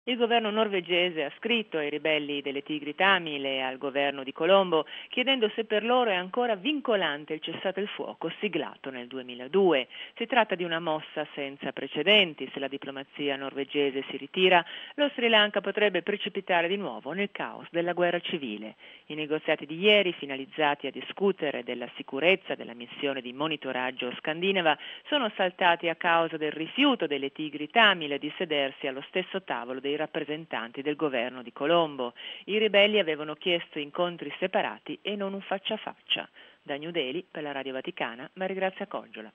(09 giugno 2006 - RV) Sono falliti a Oslo i colloqui tra i rappresentanti del governo dello Sri Lanka ed i ribelli separatisti Tamil. A confermarlo, i mediatori norvegesi, che non sono riusciti a riunire le parti al tavolo della trattative, confermando informazioni fornite in precedenza dalle autorità di Colombo. Il servizio